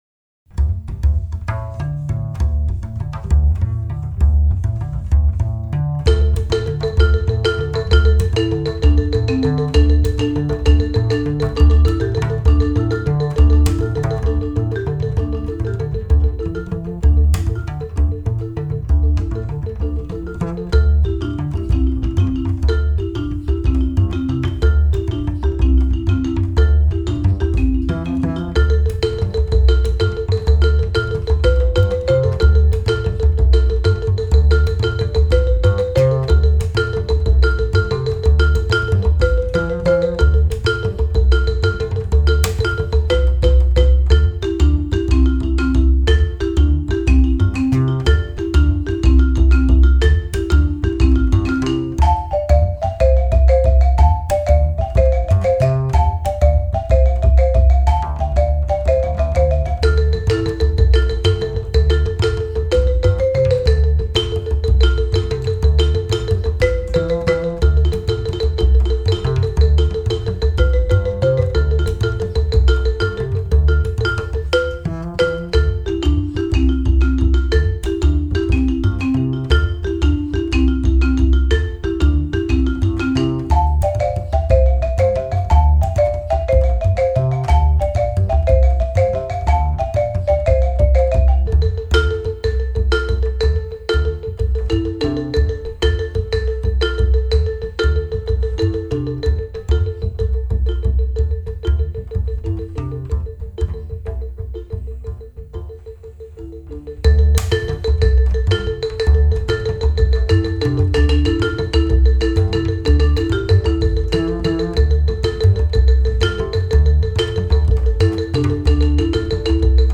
akustik